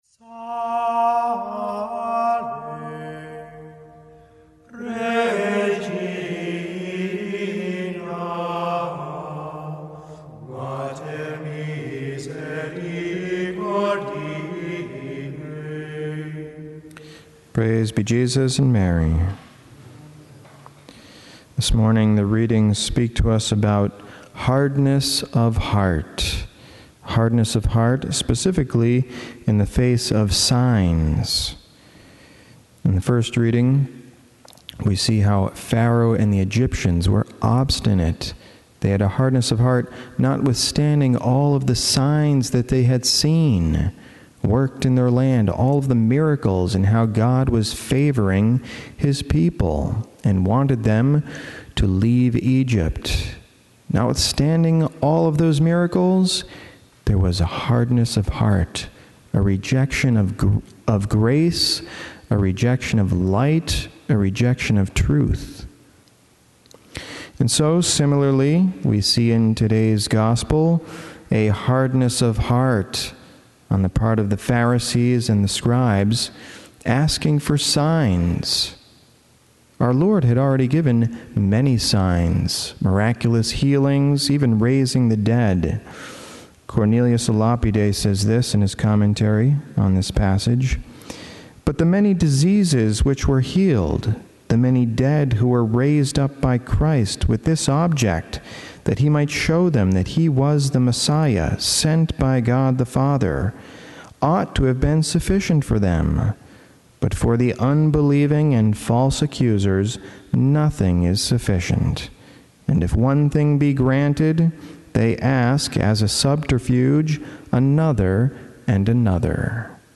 Homily
Mass: Monday 16th Week of Ordinary Time - Wkdy - Form: OF Readings: 1st: exo 14:5-18 Resp: exo 15:1-2, 3-4, 5-6 Gsp: mat 12:38-42 Audio (MP3) +++